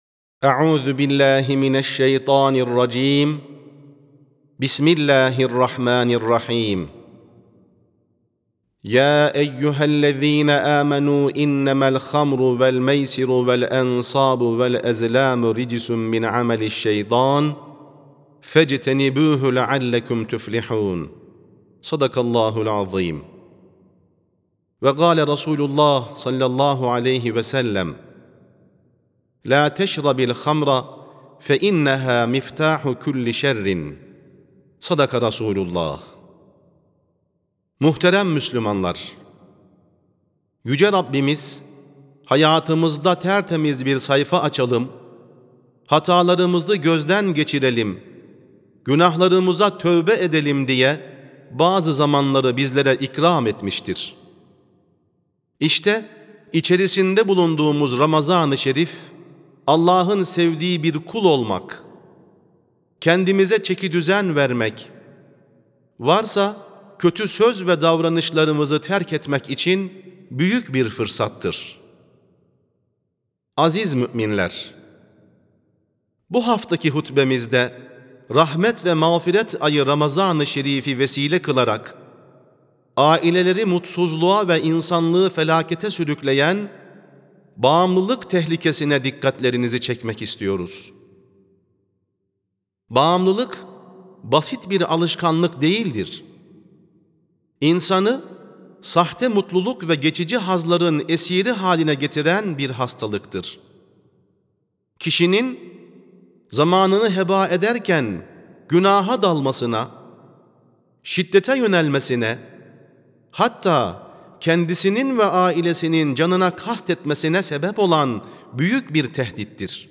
Sesli Hutbe (Bağımlılıkla Mücadelede Ramazan Bir Fırsattır).mp3